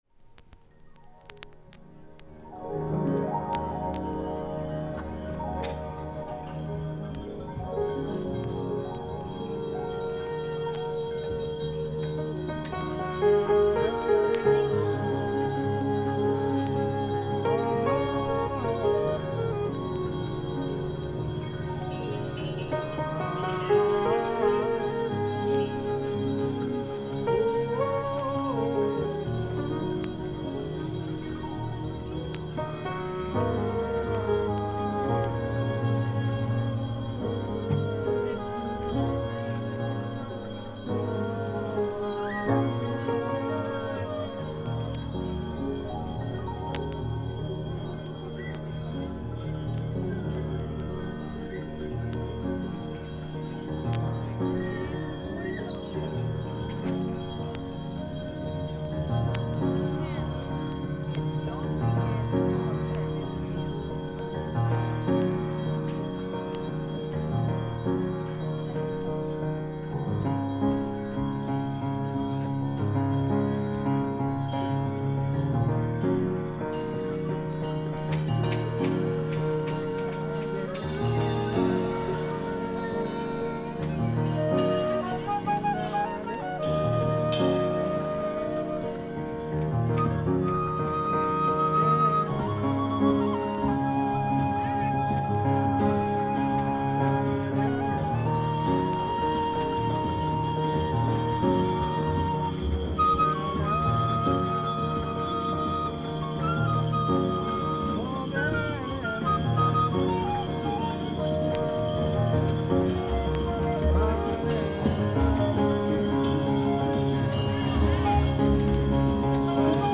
sonic spiritual excursion